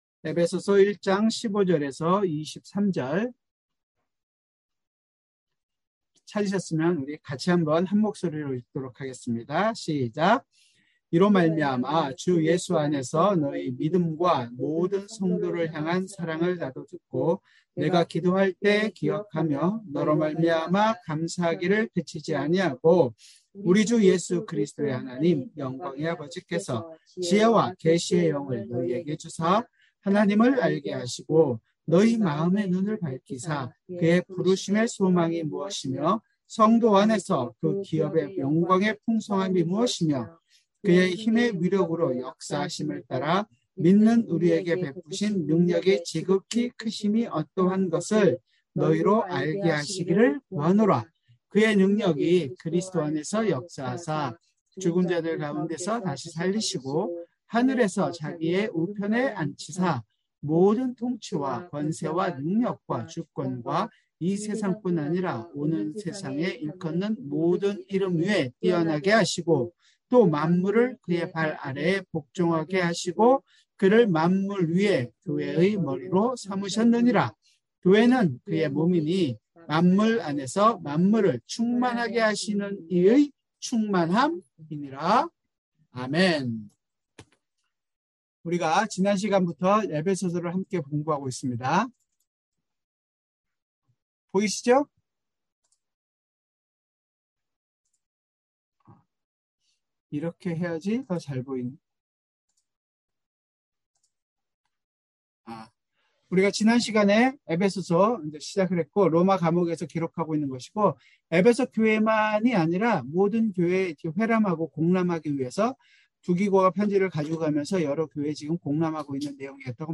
수요성경공부